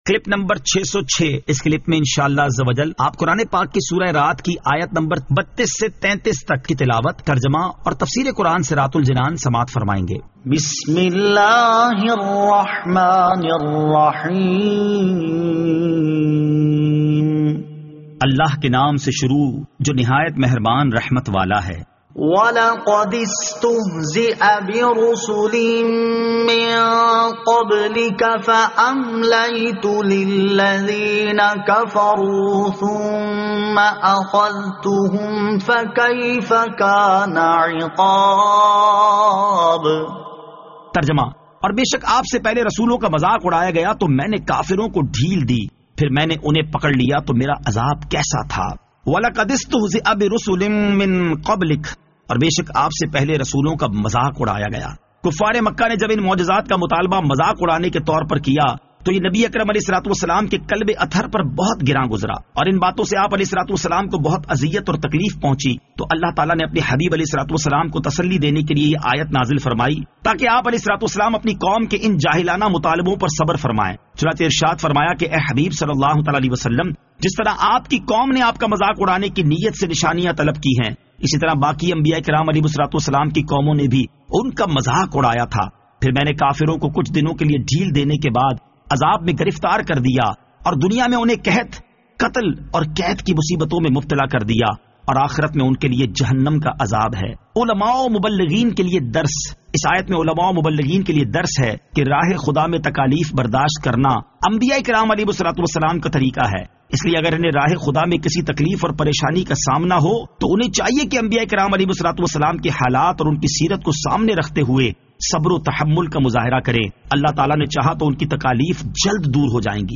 Surah Ar-Rad Ayat 32 To 33 Tilawat , Tarjama , Tafseer